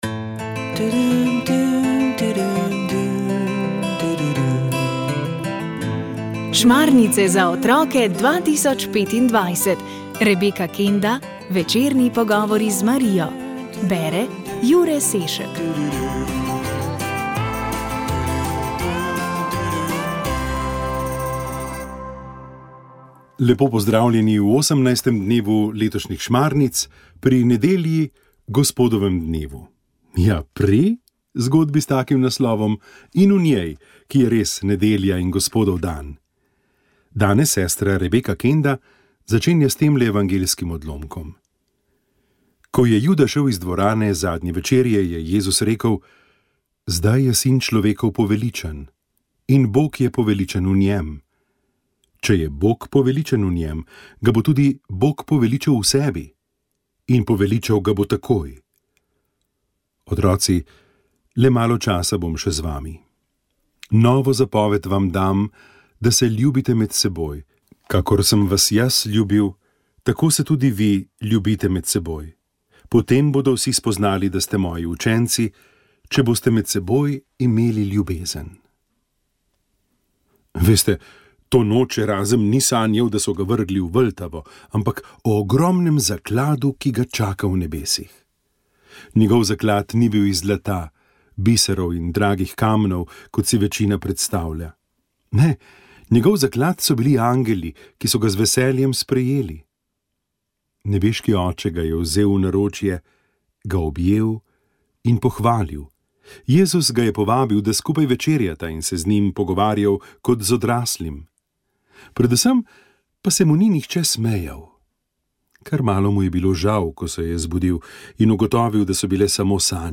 Zgodbe za otroke